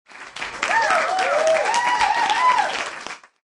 cheering.mp3